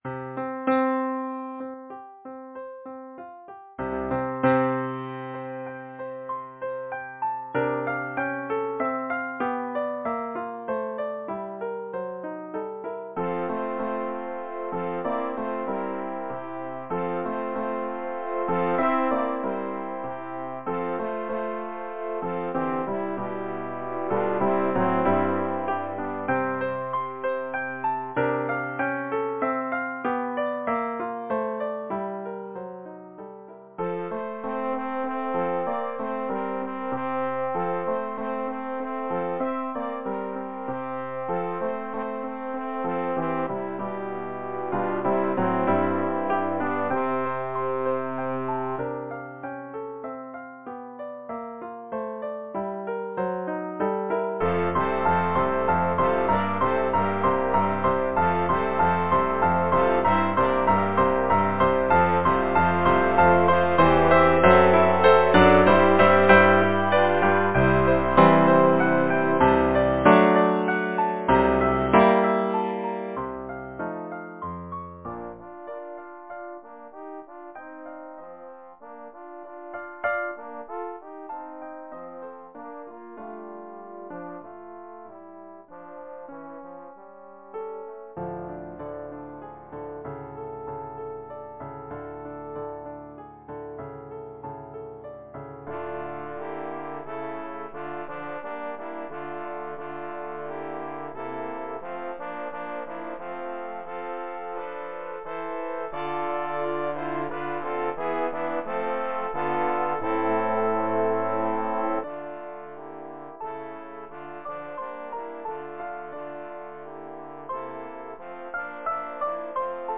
Number of voices: 4vv Voicing: SATB Genre: Secular, Partsong
Language: English Instruments: Piano